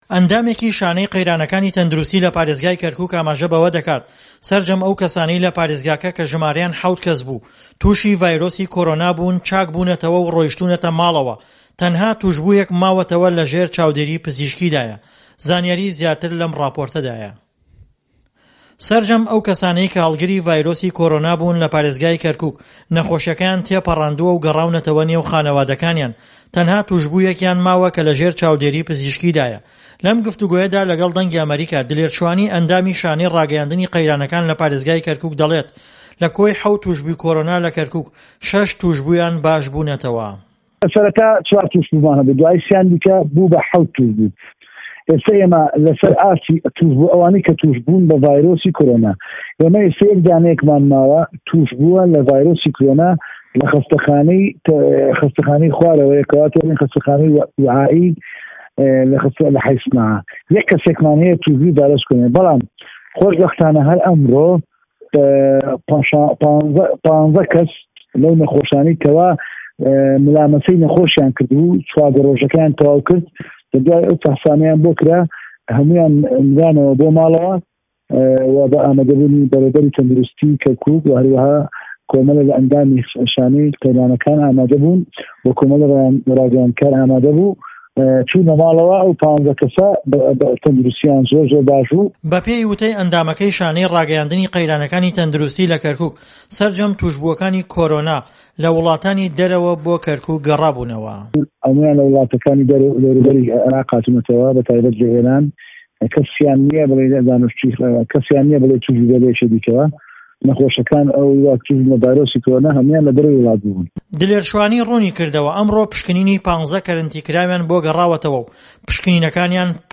ڕاپۆرتی کۆرۆنا لە شاری کەرکوک